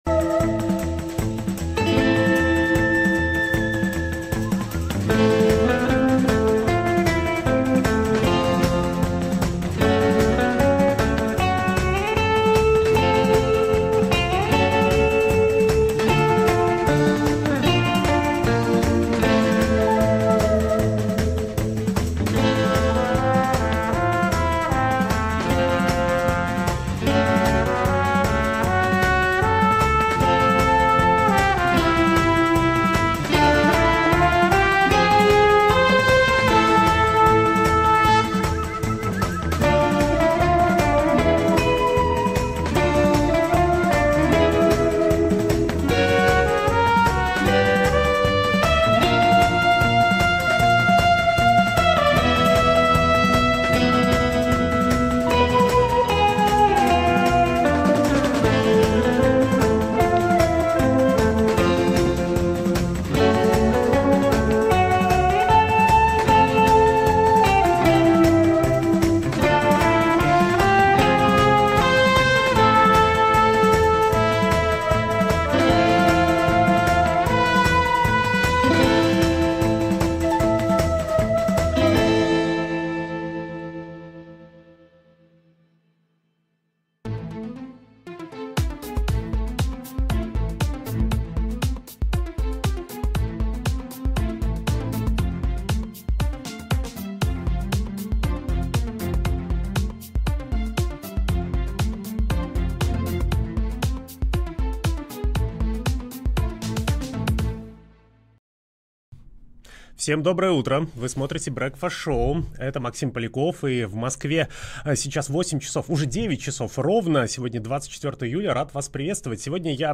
Утренний эфир с гостями